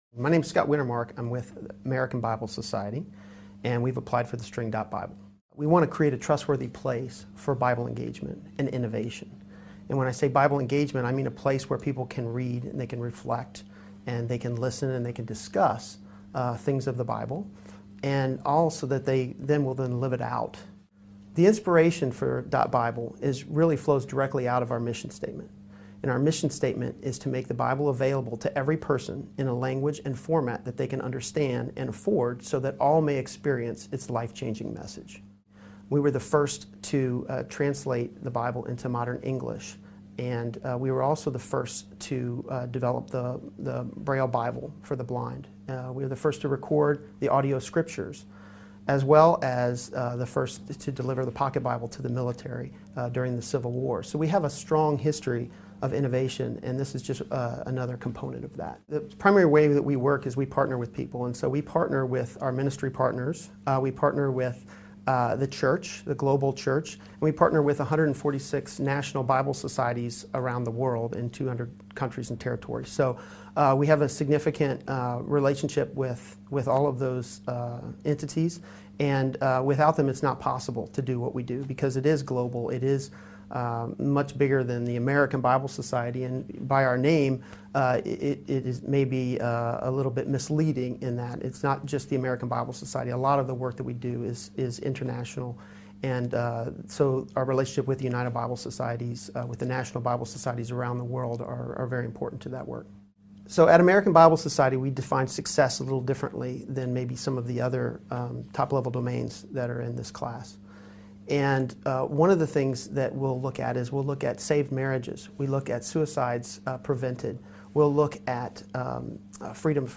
As part of ICANN's global program to raise awareness and interest in all of the New gTLDs, we invited all New gTLD applicants to participate in a series of recorded interviews. The short videos provide insight into the individual story of a TLD, or portfolio of TLDs, the inspiration behind it and how they see it being used.